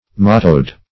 Mottoed \Mot"toed\, a.
mottoed.mp3